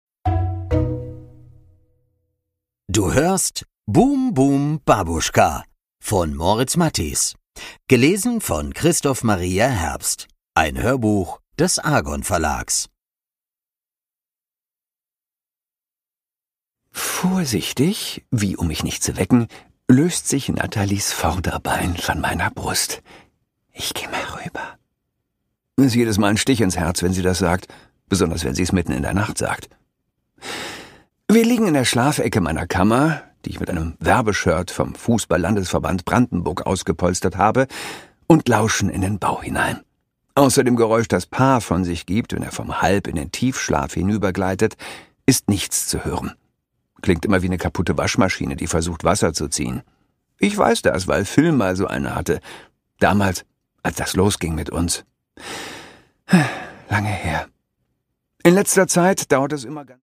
Produkttyp: Hörbuch-Download
Gelesen von: Christoph Maria Herbst